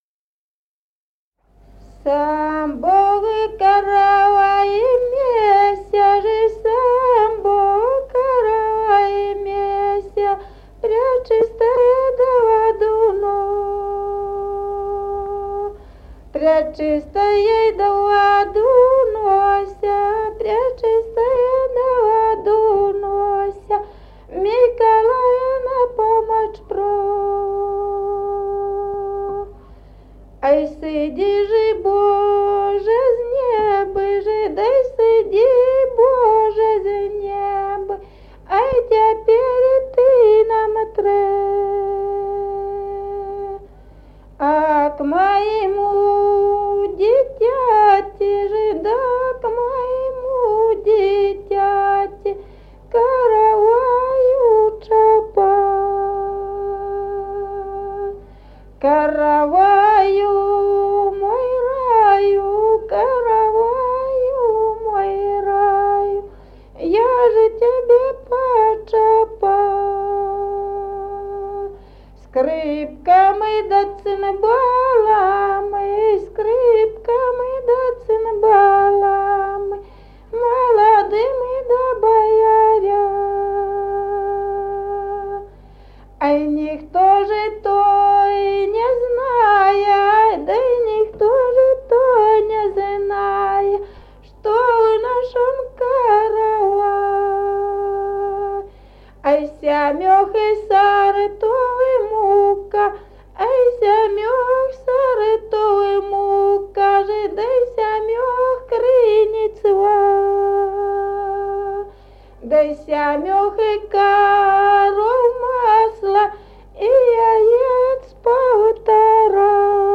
Народные песни Стародубского района «Сам Бог каравай меся», свадебная.
с. Мо́хоновка.